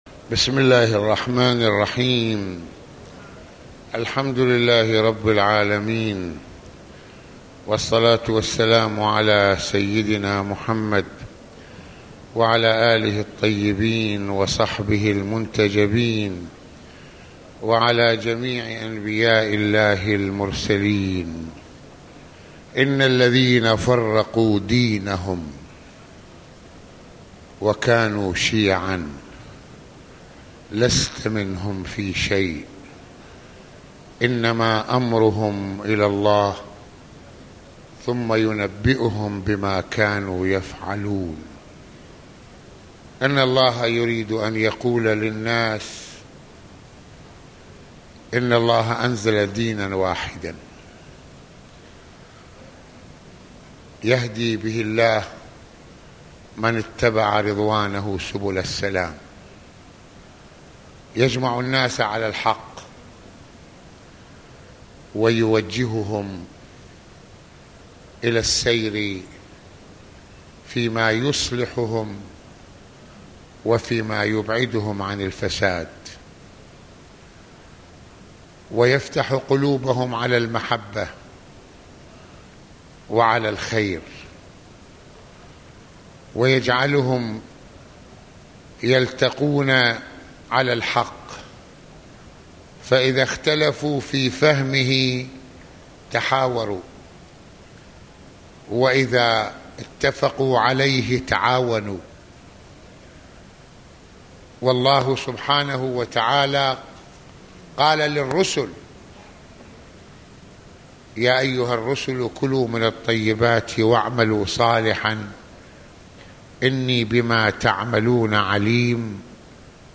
المناسبة: موعظة ليلة الجمعة المكان: مسحد الإمامين الحسنين (ع)